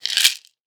Percs
West MetroPerc (43).wav